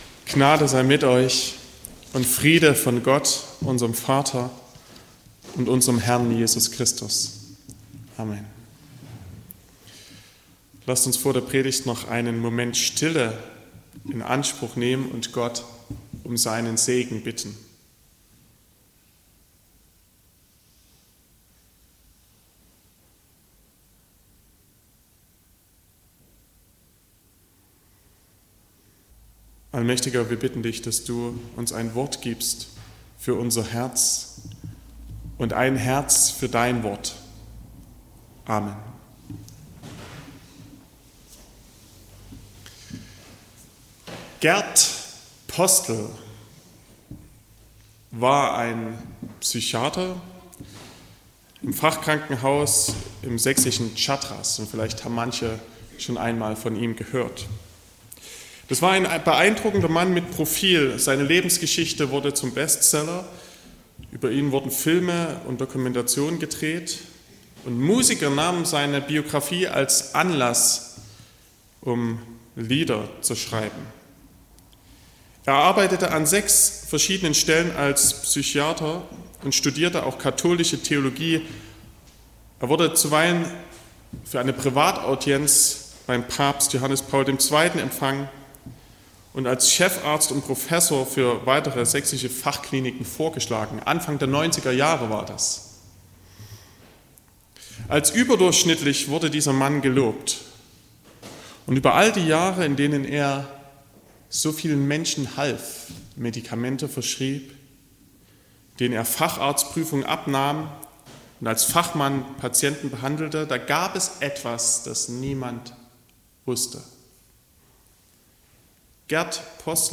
02.06.2024 – Gottesdienst
Predigt (Audio): 2024-06-02_Wer_ist_Jesus_fuer_mich_.mp3 (12,0 MB)